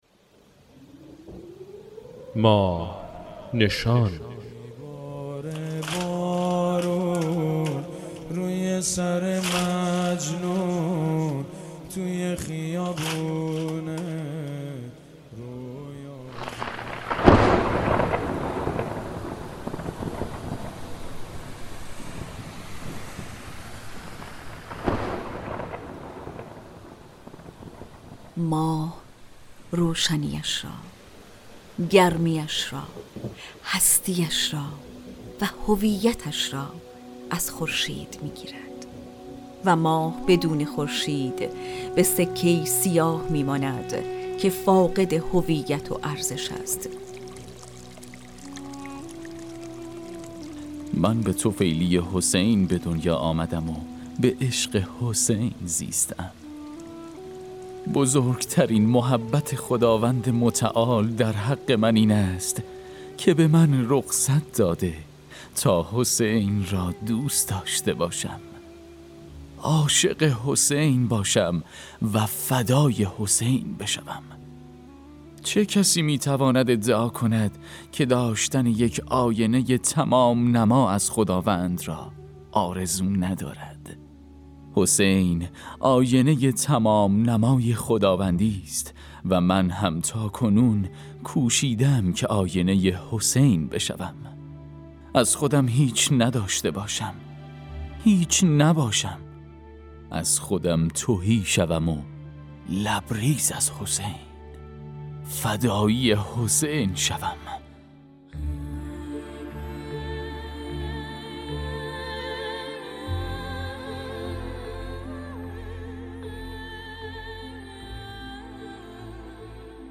برش‌هایی از این کتاب را در ماه‌نشان با هم‌ می‌شنویم.